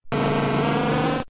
Cri de Dardargnan K.O. dans Pokémon Diamant et Perle.